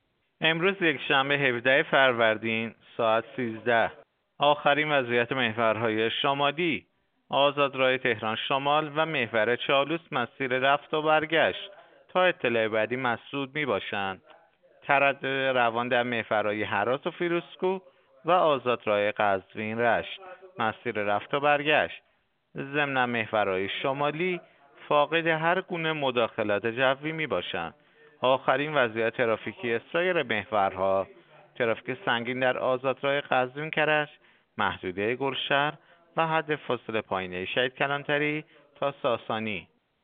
گزارش رادیو اینترنتی از آخرین وضعیت ترافیکی جاده‌ها ساعت ۱۳ هفدهم فروردین؛